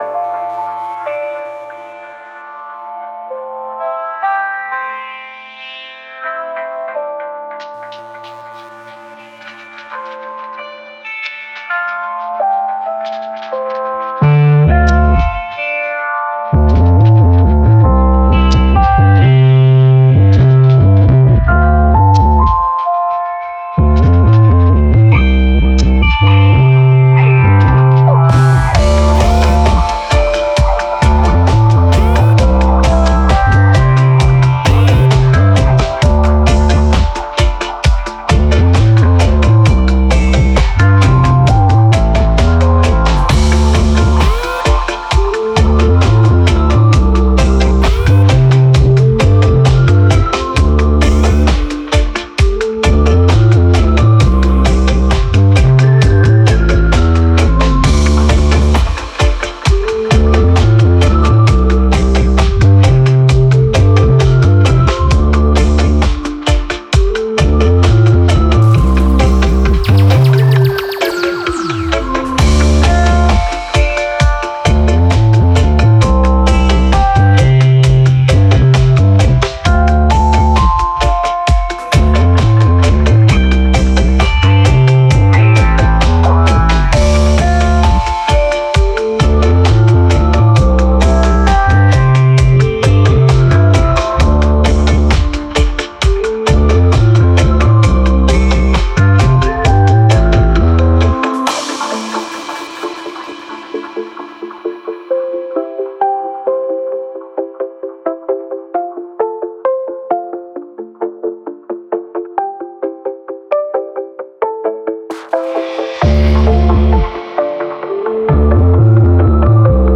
Genre Indietronica